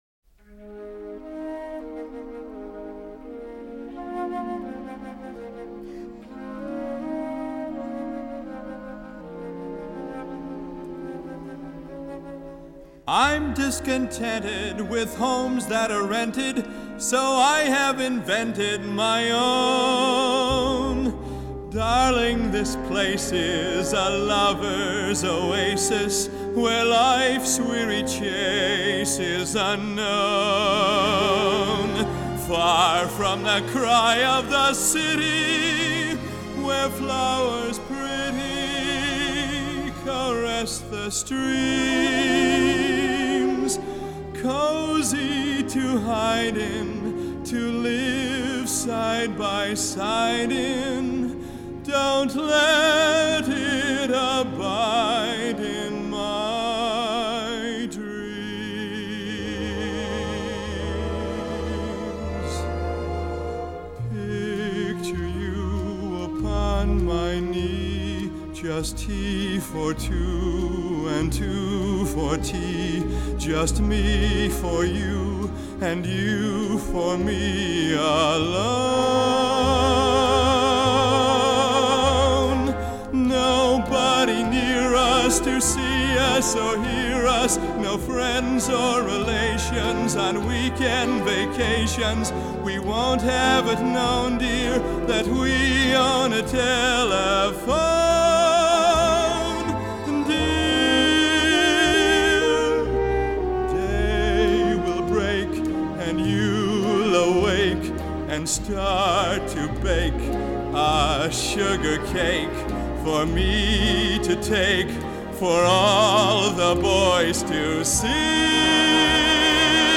1925   Genre: Musical   Artist